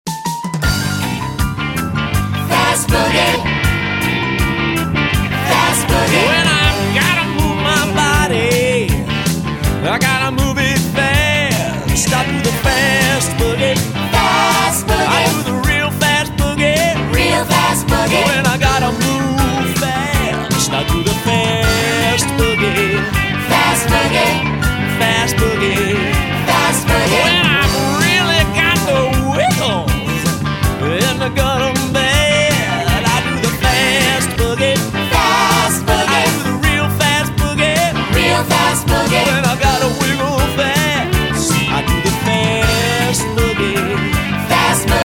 movement songs